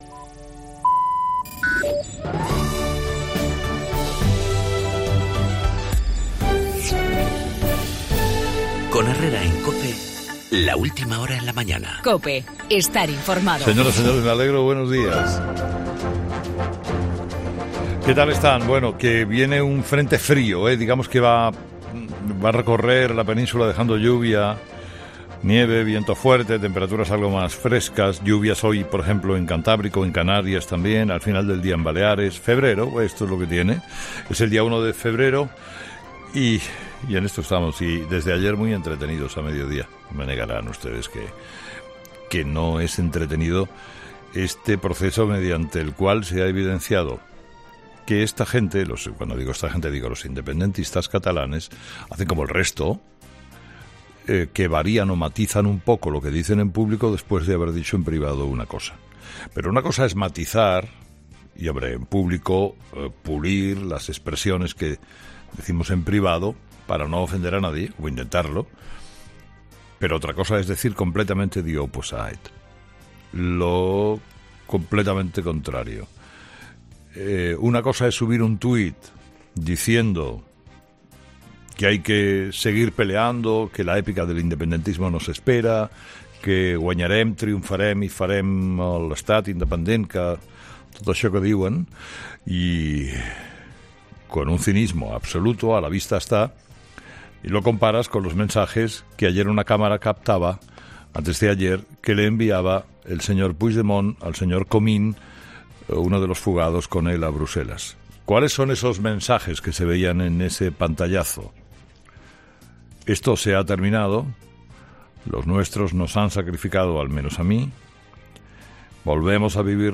Monólogo 8h, 1 de febrero de 2018